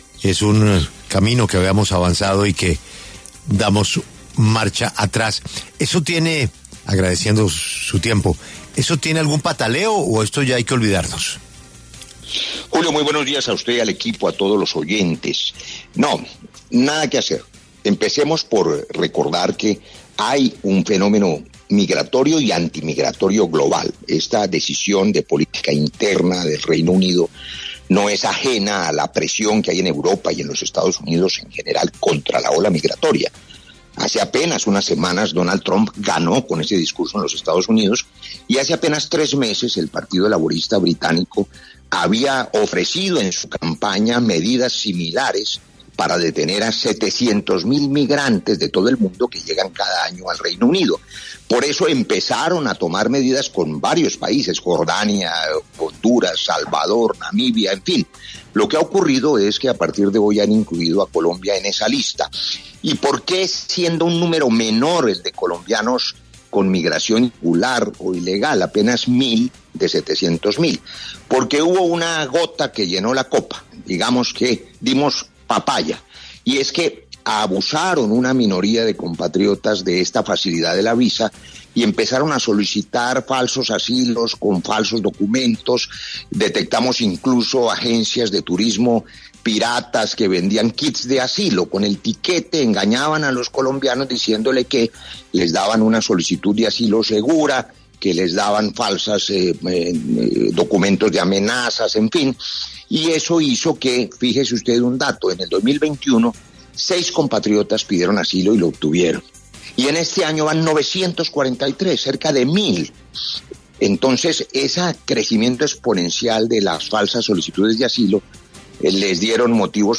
El embajador de Colombia en Reino Unido, Roy Barreras, pasó por los micrófonos de La W para hablar sobre esta decisión tomada por el gobierno británico, asegurando queya no hay nada que hacer para contrarrestar la decisión.
Embajador Roy Barreras habla de la medida de Reino Unido de vovler a pedir visa a colombianos